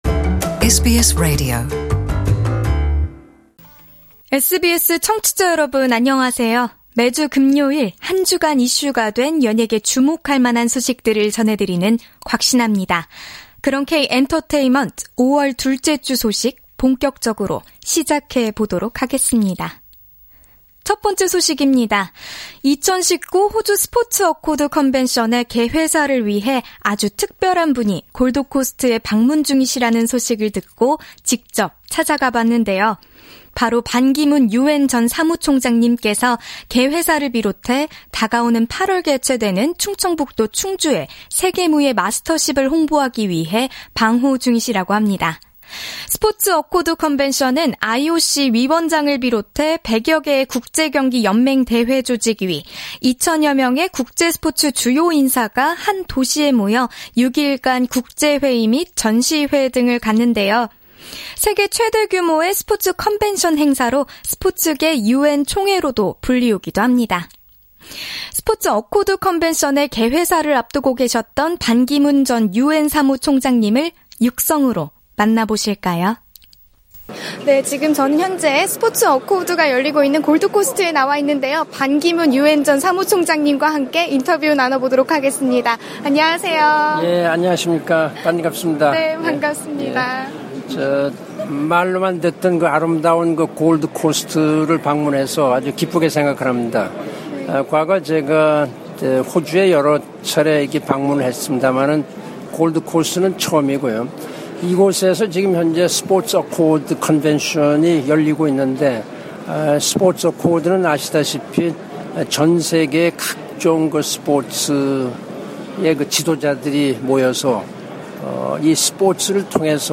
[Exclusive Interview] Ban Ki-moon expects Australia of more contributions to human right improvement